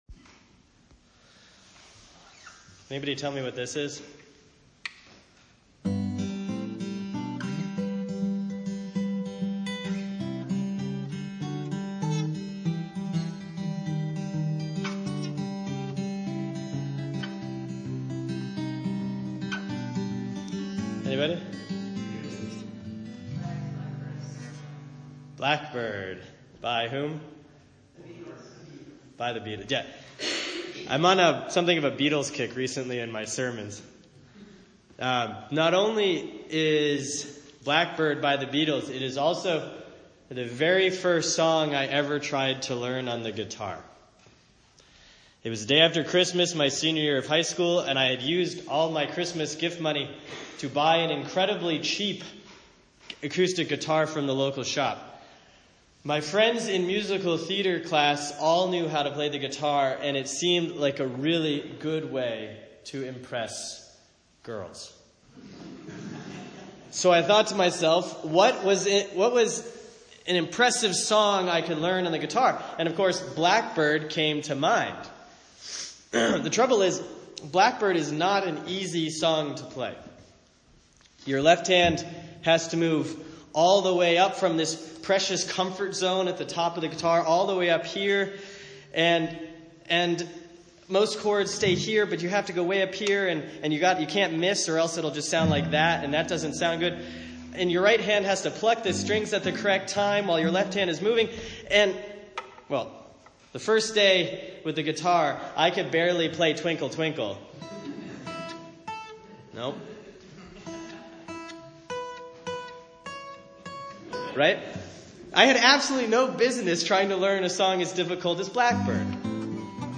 (Sermon for Sunday, July 7, 2013 || Proper 9C || 2 Kings 5:1-14)